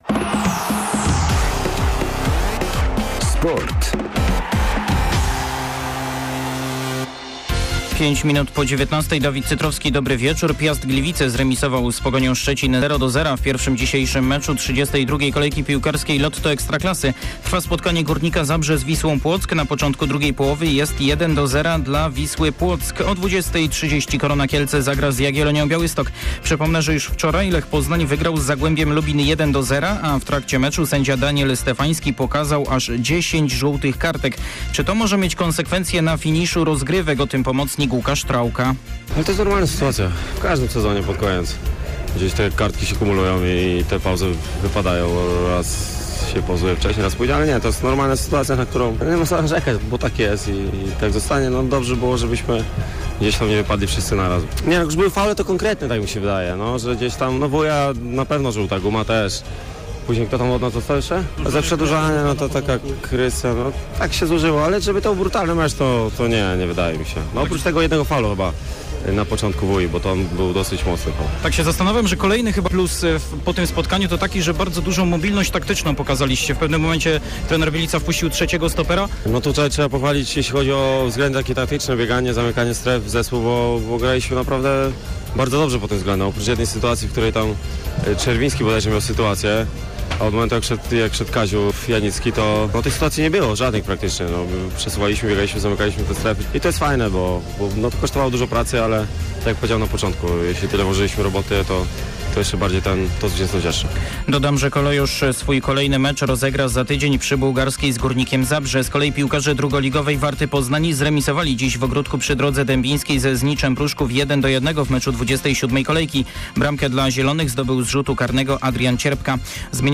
21.04 serwis sportowy godz. 19:05